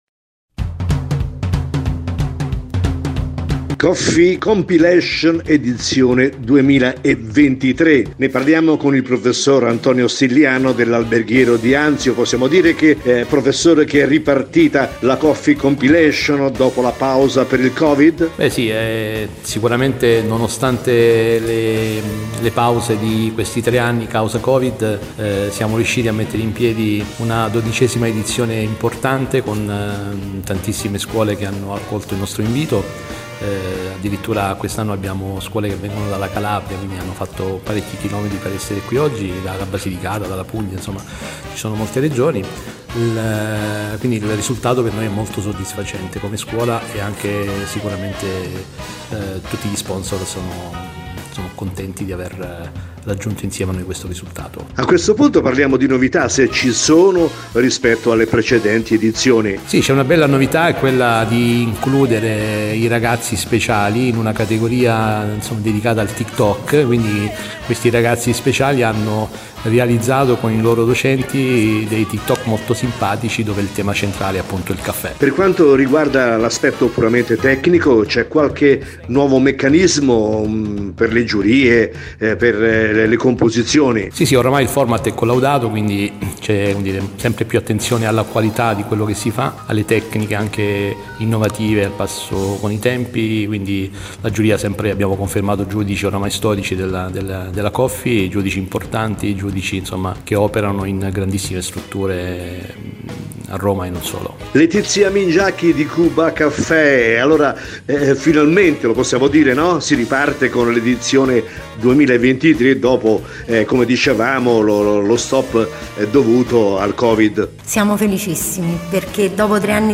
intervista-montata.mp3